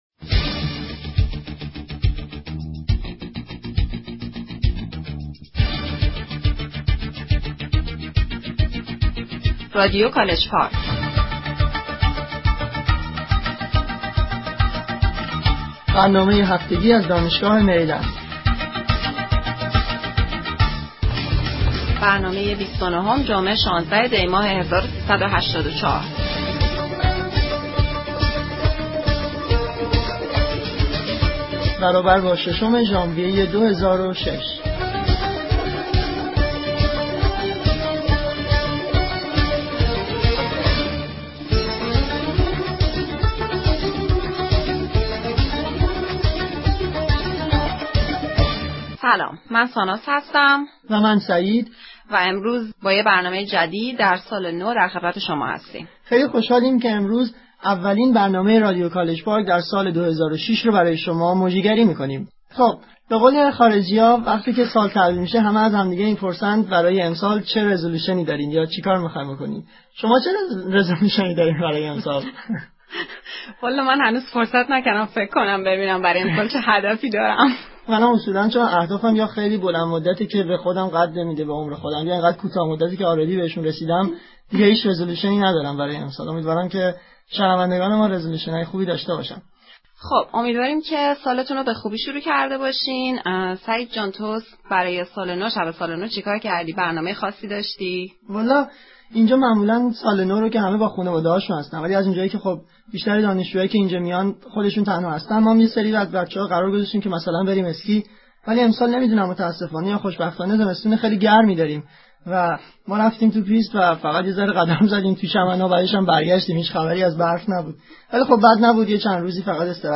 Persian Classic Music